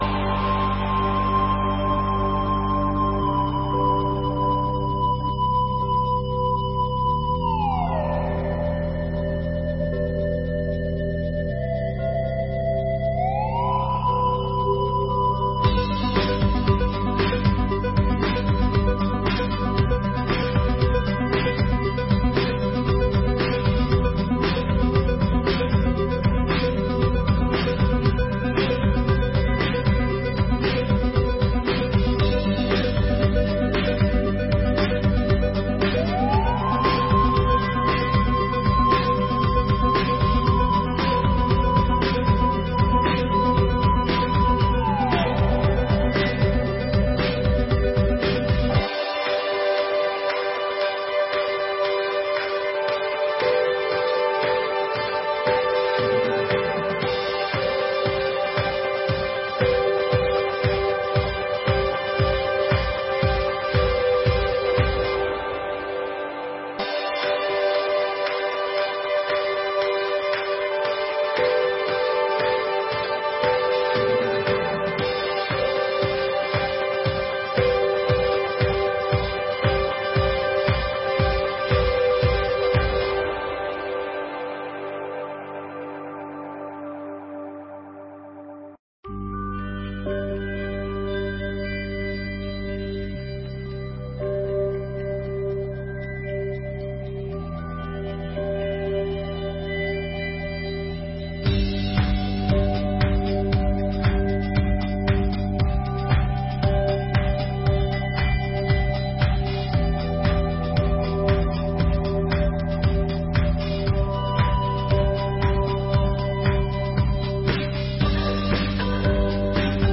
22ª Sessão Ordinária de 2024